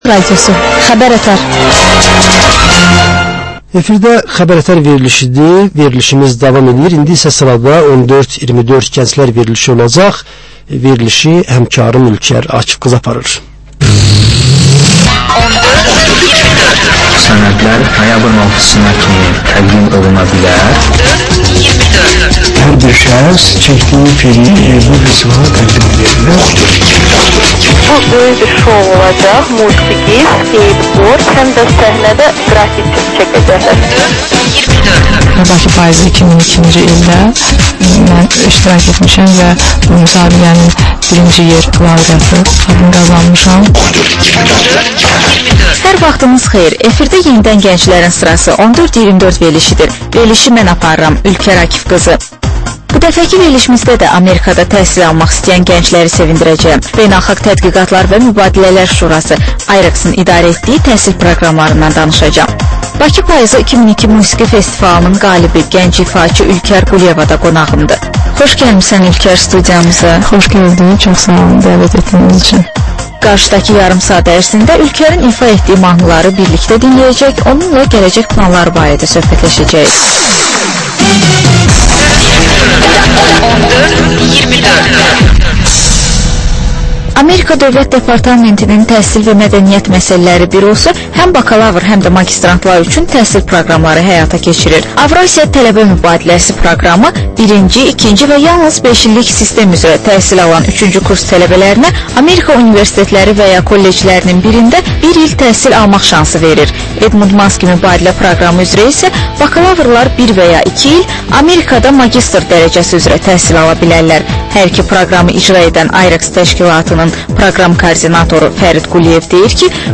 Reportajç müsahibə, təhlil